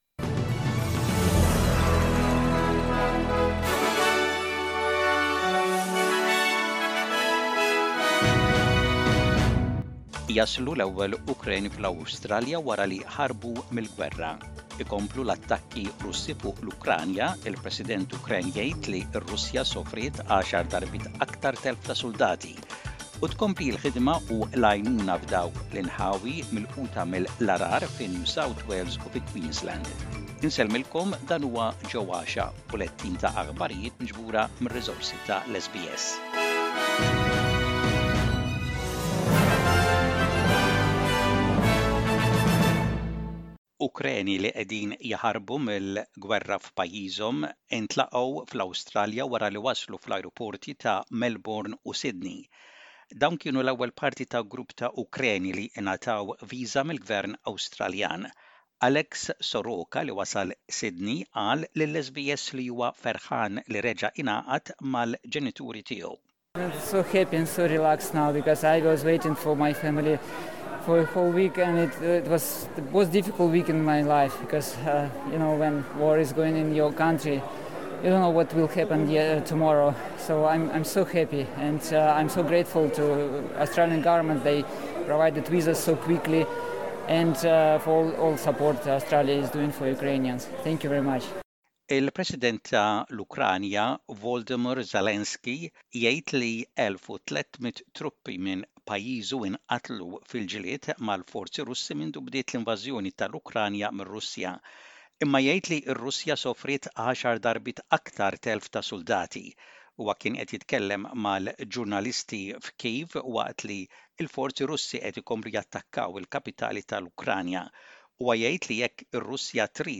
SBS Radio | Maltese News: 18/03/22